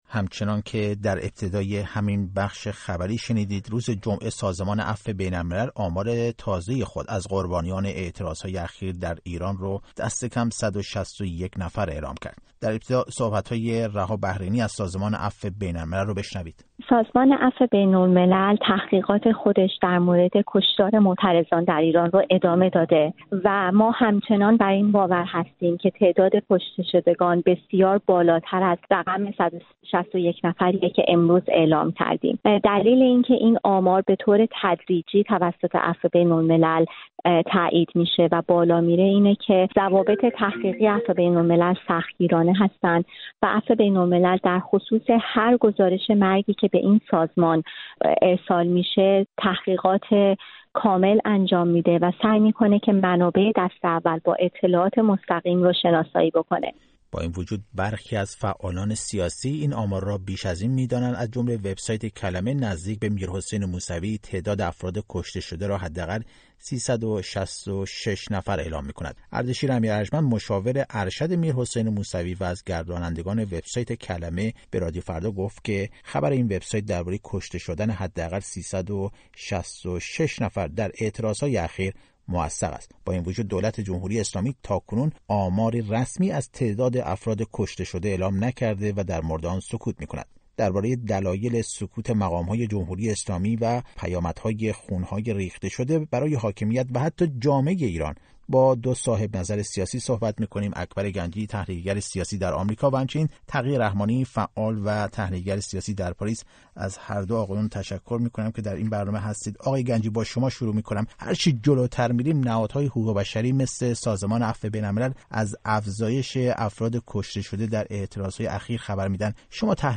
پیامد خون‌های ریخته شده برای حاکمیت چه خواهد بود؟ گفت‌وگو با اکبر گنجی و تقی رحمانی